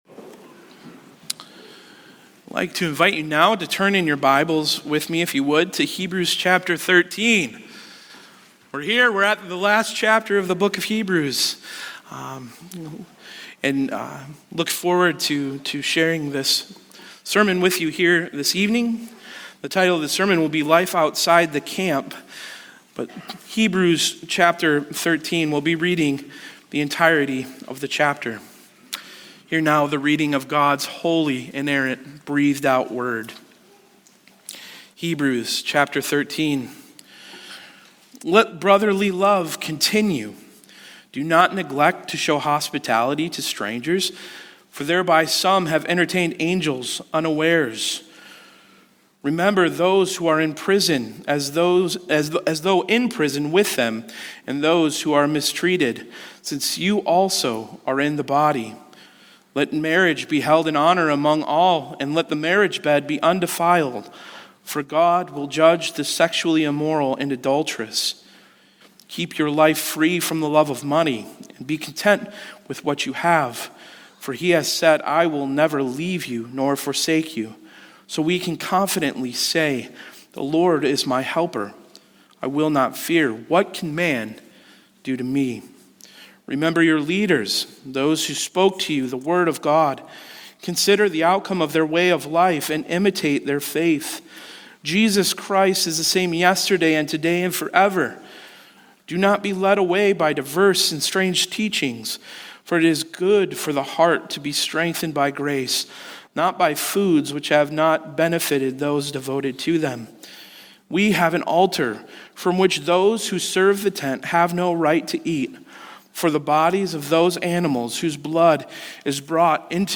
Life Outside the Camp | SermonAudio Broadcaster is Live View the Live Stream Share this sermon Disabled by adblocker Copy URL Copied!